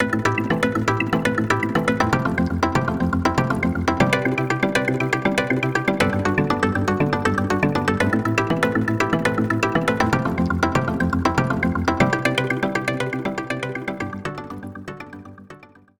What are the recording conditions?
No other processing was done on these sounds other than the onboard effects included with the instrument.